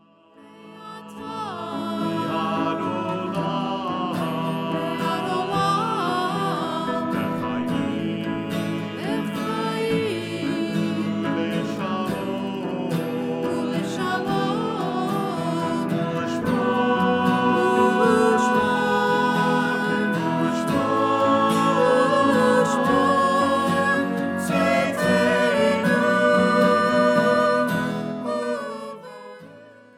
adult choir, instrumental ensemble